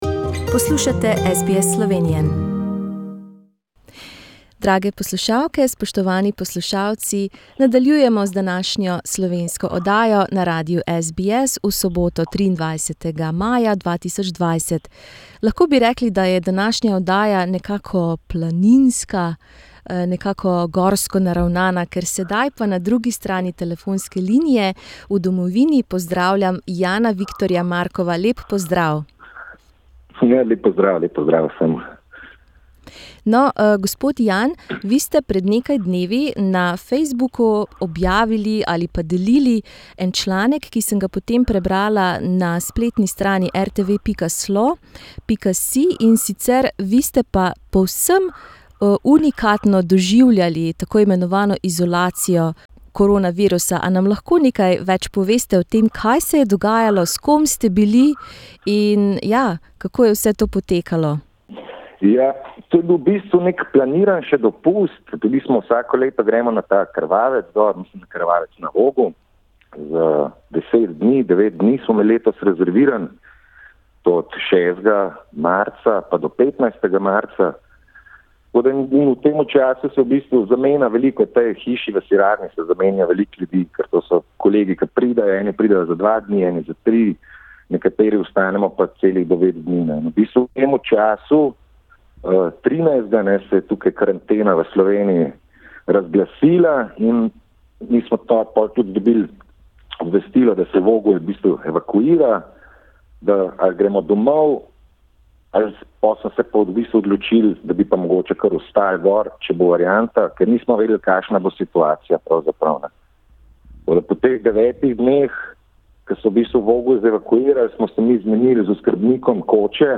Find out how they coped with limited food and water supplies in this interview…